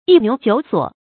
發音讀音
yī niú jiǔ suǒ